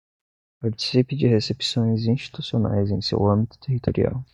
Read more scope, field, context Frequency 26k Pronounced as (IPA) /ˈɐ̃.bi.tu/ Etymology Borrowed from Latin ambitus In summary Borrowed from Latin ambitus.